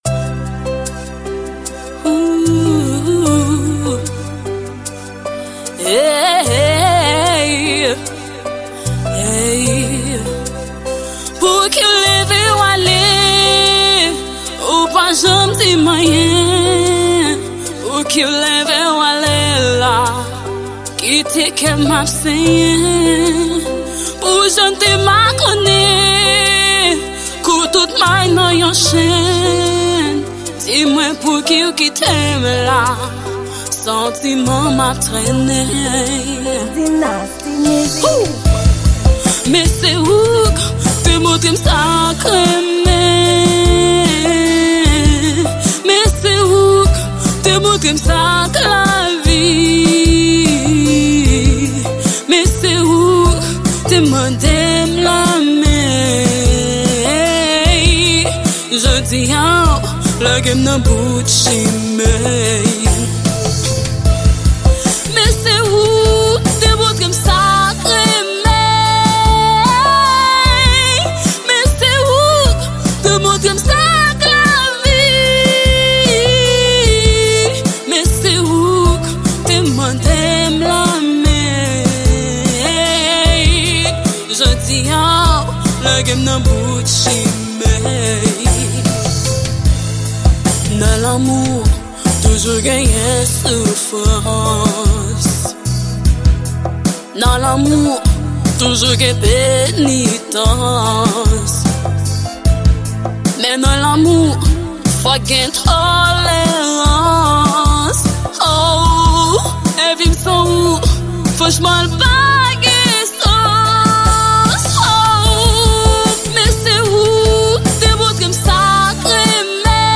Genre: R&B